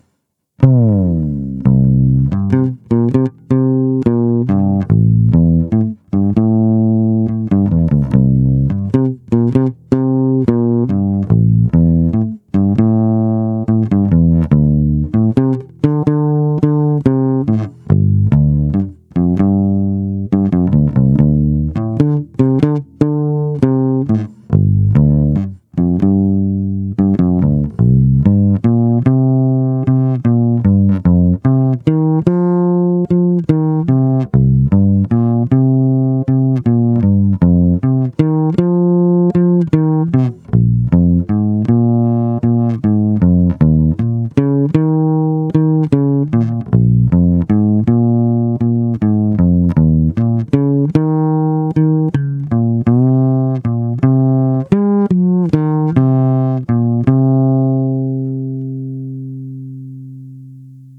Zvuk je opravdu hutný a i díky piezo snímači dostává akustický, až kontrabasový nádech. Ostatně posuďte sami z nahrávek, které jsou pořízené přes zvukovku do PC, bez úprav.
Krkový snímač
Máš moc velkou citlivost na vstupu, takže je signál ořezaný, zkreslený a proto to ve zvuku chrčí.